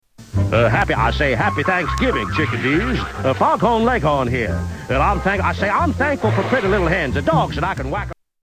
Tags: Holiday Thansgiving Cartoons Thanksgiving Cartoon clips Thansgiving clips